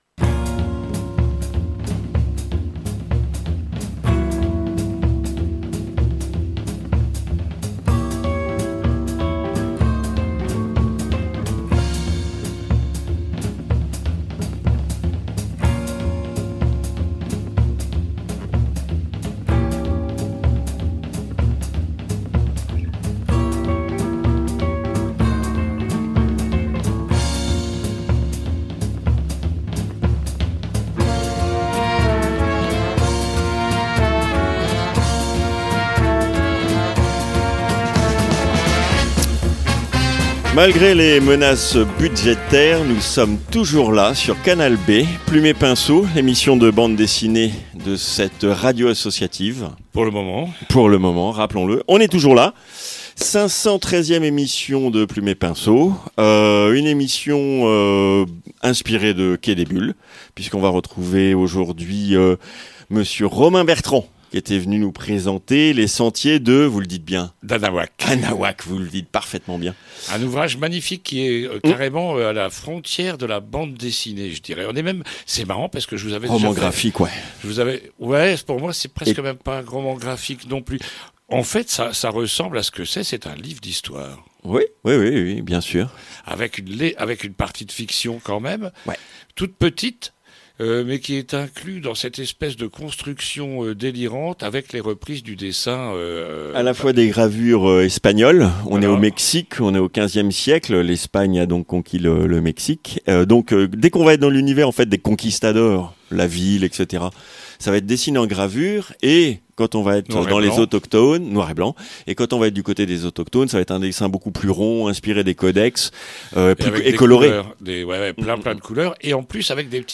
I - INTERVIEW